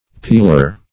pilour - definition of pilour - synonyms, pronunciation, spelling from Free Dictionary Search Result for " pilour" : The Collaborative International Dictionary of English v.0.48: Pilour \Pil"our\, n. A piller; a plunderer.